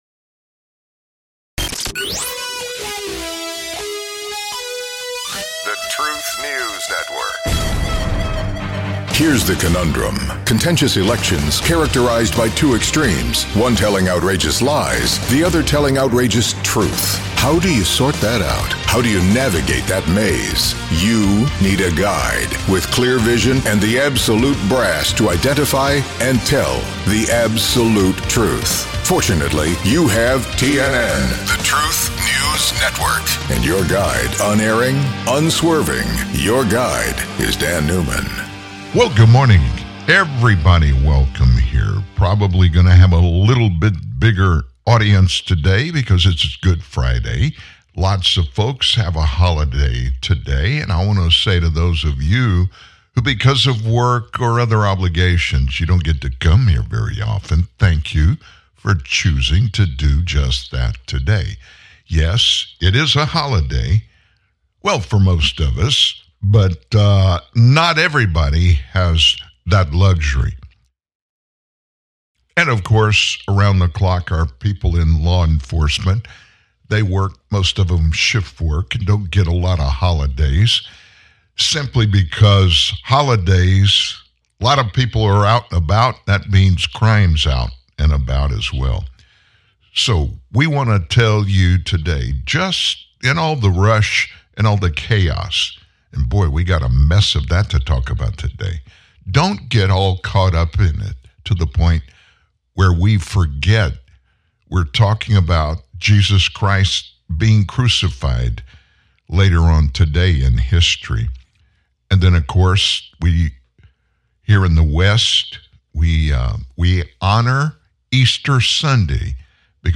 Hillary Clinton was out over the weekend selling the fact that our government MUST cancel Section 230 to give the federal government total control over Americans' speech! (You'll hear Hillary spell that out in her own words).